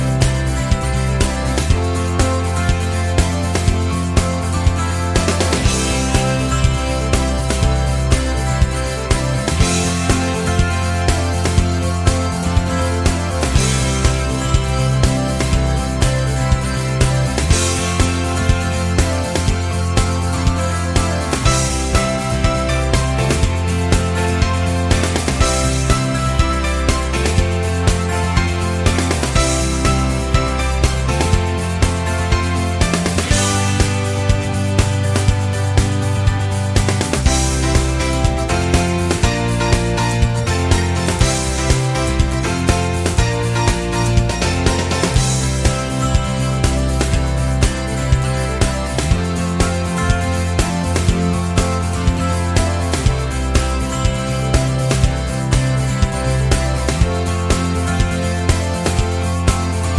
no Backing Vocals Indie / Alternative 4:03 Buy £1.50